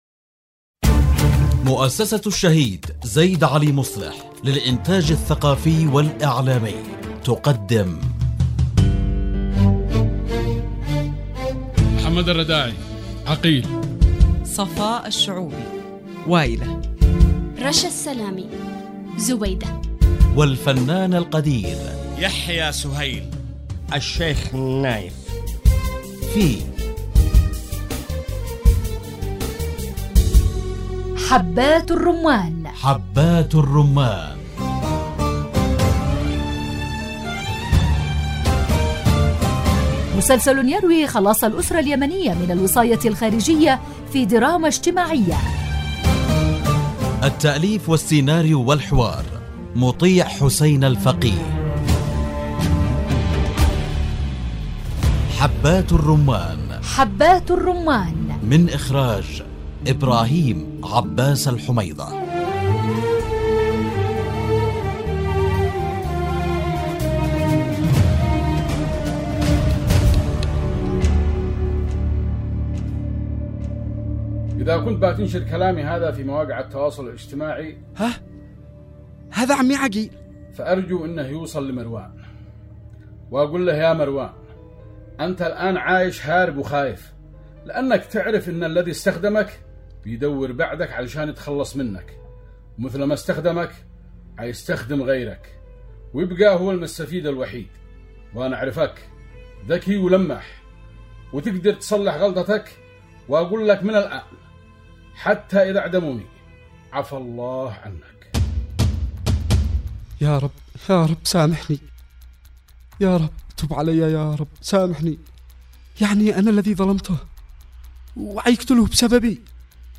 مسلسل يحكي خلاص الأسرة اليمنية من الوصاية الخارجية في دراما اجتماعية مع ألمع نجوم الشاشة اليمنية